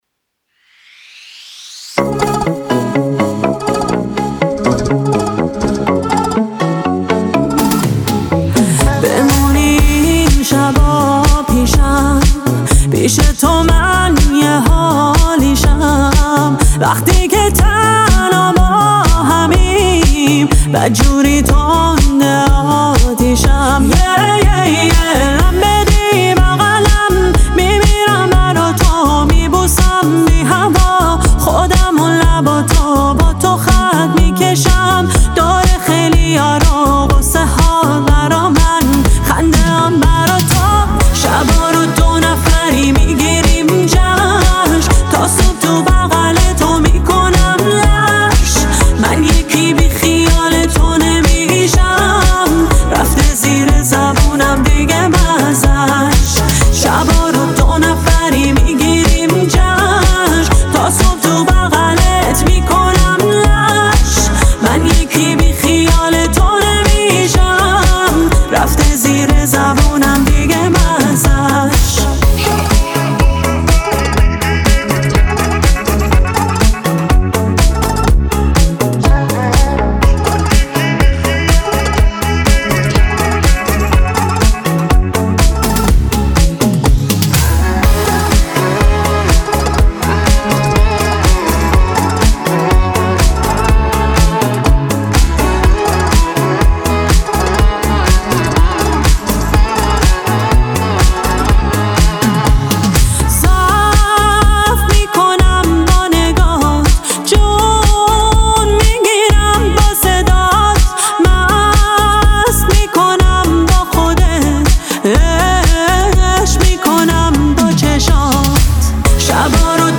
پاپ
آهنگ با صدای زن